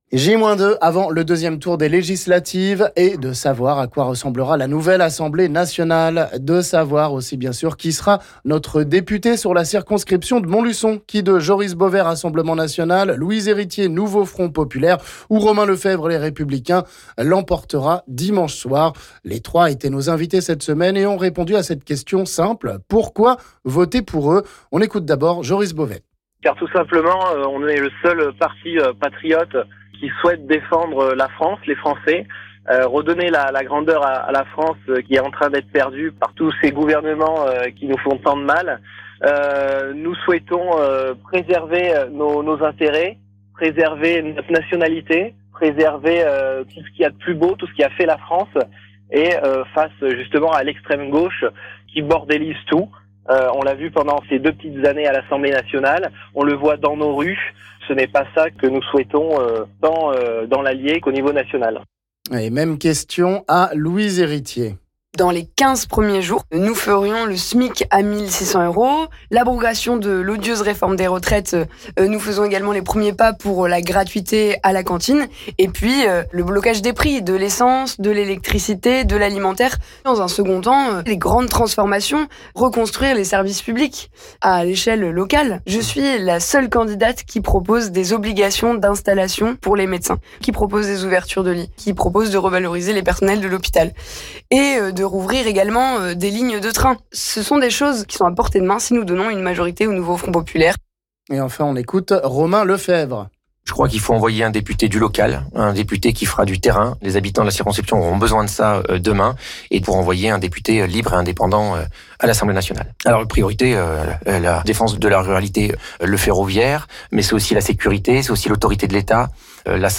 Derniers arguments pour convaincre, avant le 2ème tour des législatives sur Montluçon, les 3 candidats s'expriment une dernière fois sur RMB